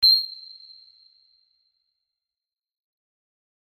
コン
/ F｜演出・アニメ・心理 / F-80 ｜other 再構成用素材